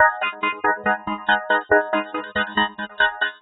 tx_synth_140_metaloop_CMaj.wav